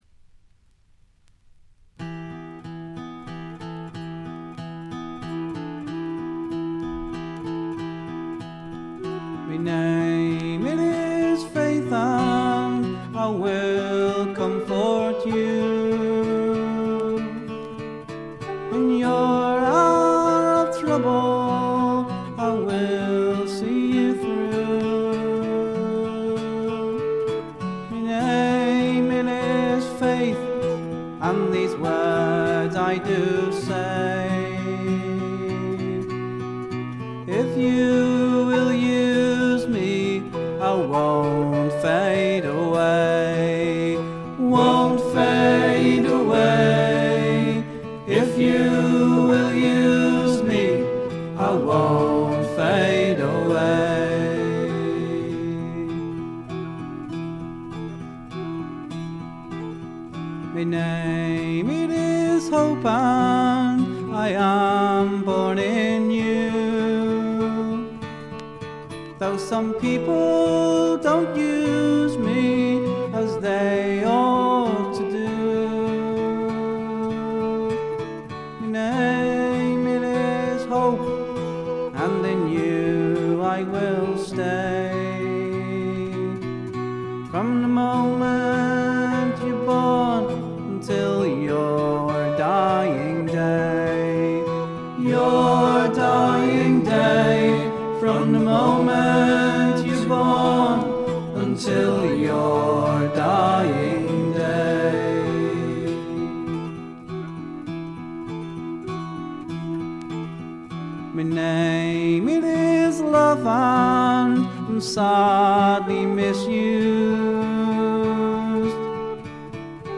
見た目に反してプレスがいまいちのようで、ところどころでチリプチ。プツ音少々。
リヴァプールの男性4人組フォークバンドによるメジャー級の素晴らしい完成度を誇る傑作です。
格調高いフォークロックの名盤。
試聴曲は現品からの取り込み音源です。
Recorded At - Canon Sound Studio, Chester